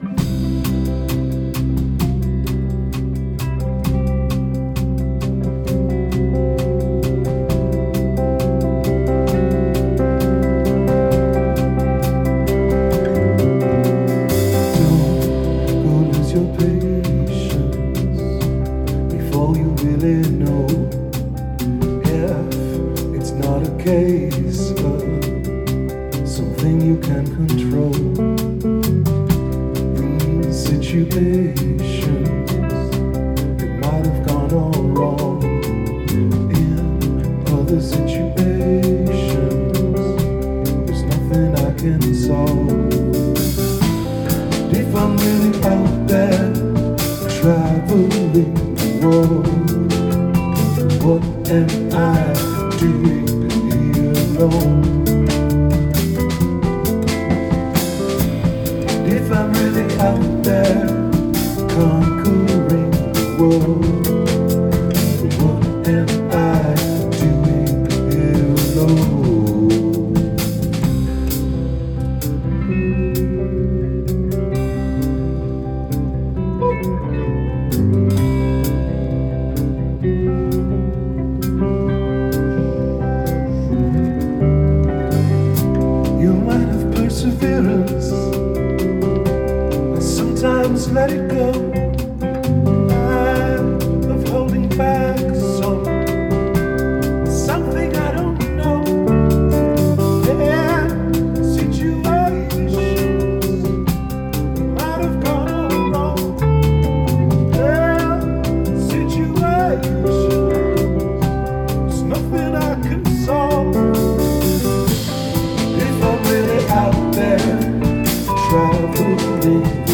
Rehearsals 6.9.2013